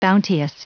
Prononciation du mot bounteous en anglais (fichier audio)
Prononciation du mot : bounteous